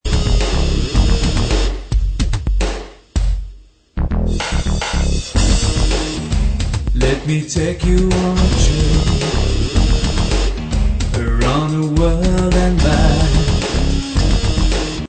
électro-pop